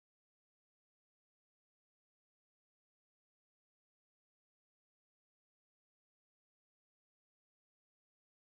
• 113 Bpm Modern Drum Loop Sample E Key.wav
Free drum loop - kick tuned to the E note.
113-bpm-modern-drum-loop-sample-e-key-PJL.wav